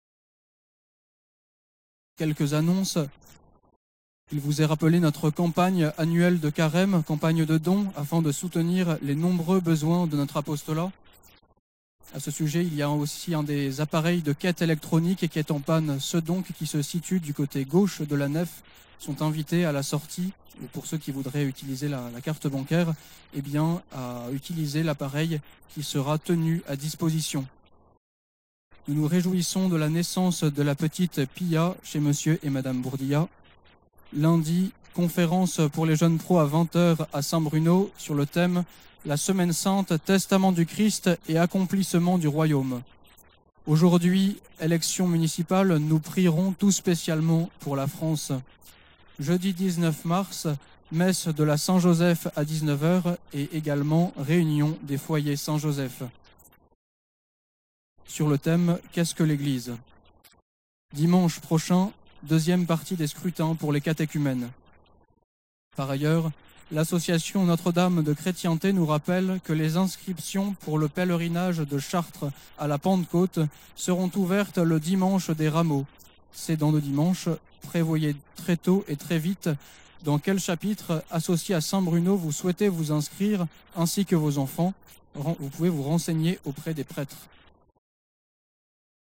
Annonces de la semaine